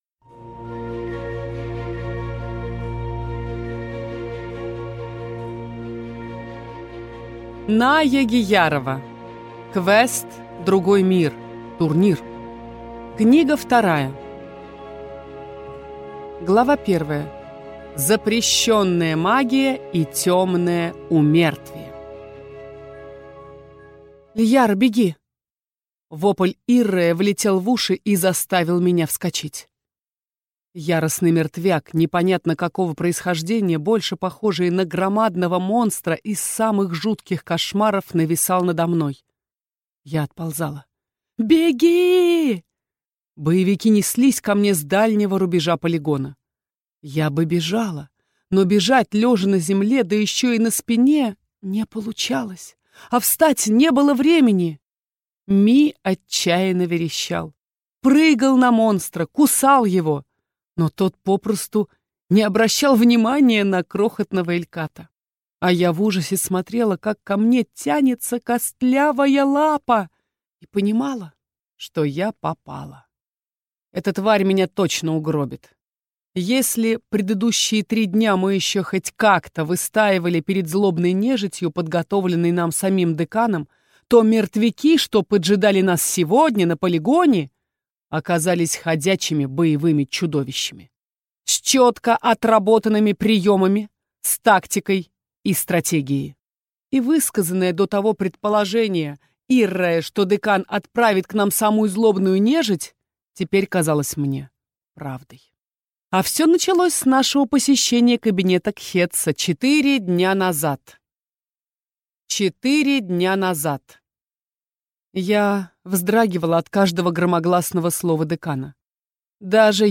Аудиокнига Квест «Другой мир». Турнир | Библиотека аудиокниг